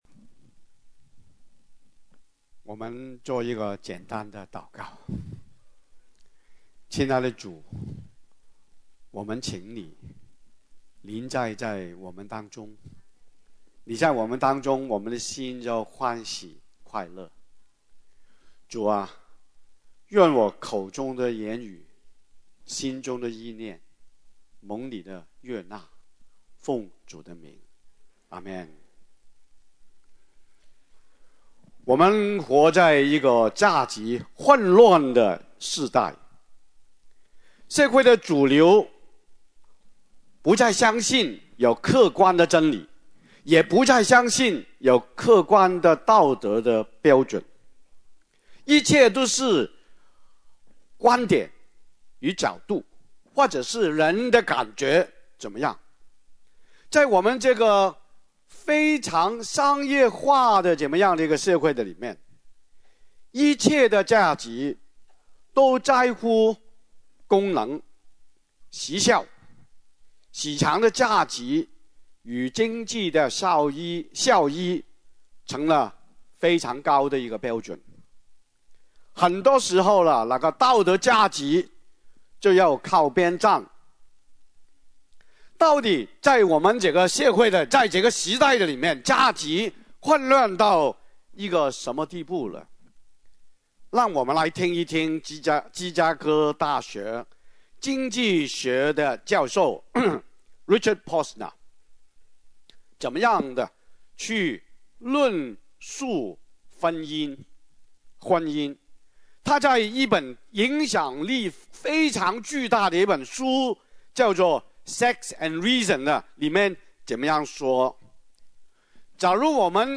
IMS Sermons